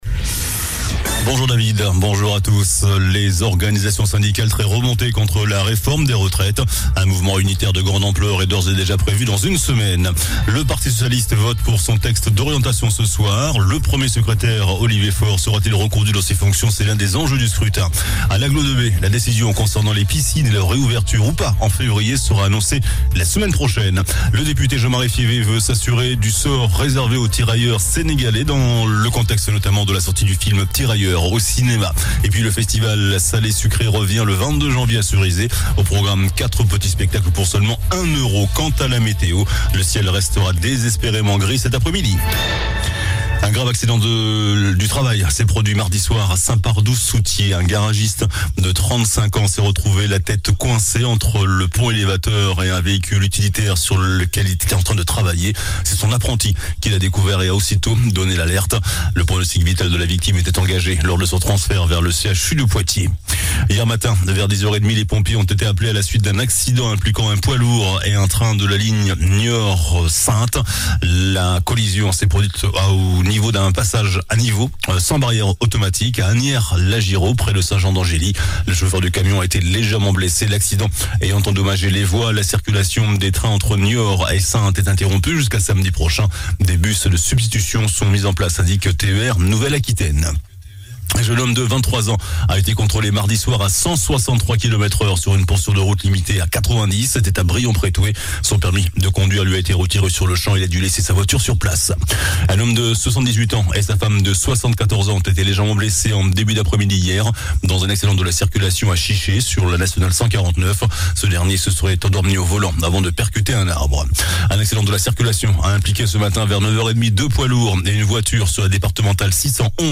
JOURNAL DU JEUDI 12 JANVIER ( MIDI )